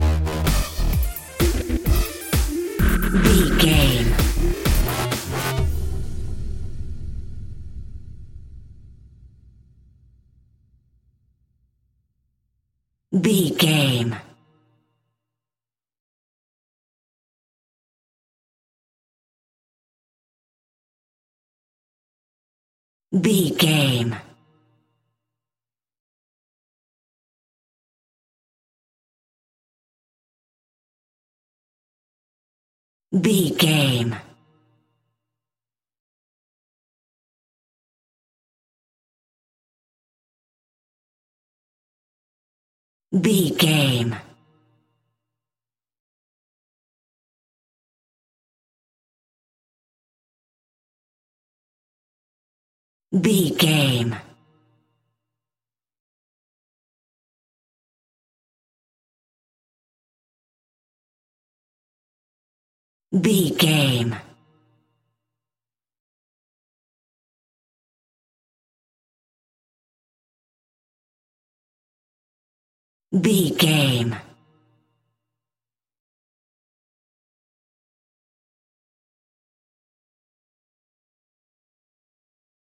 Cool Dubstep Cue Stinger.
Aeolian/Minor
Fast
aggressive
powerful
dark
groovy
futuristic
industrial
frantic
drum machine
synthesiser
breakbeat
energetic
synth leads
synth bass